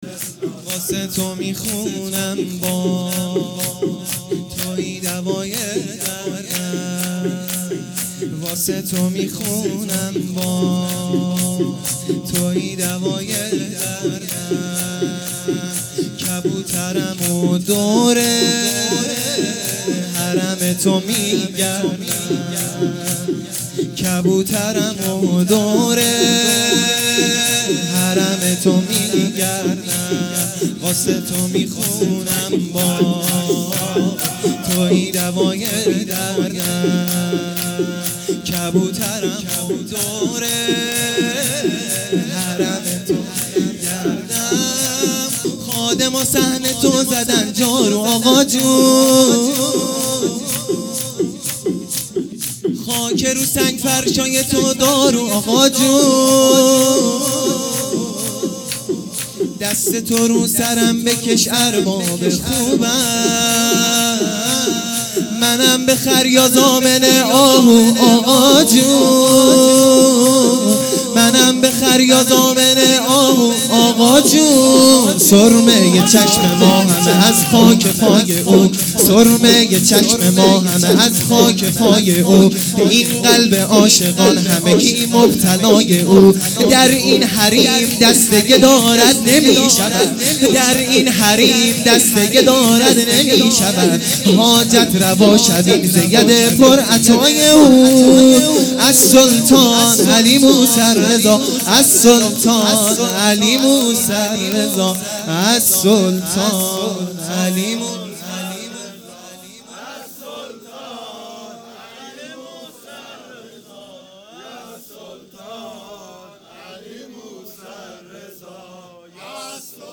شور ا خادما صحن تو زدن جارو آقاجون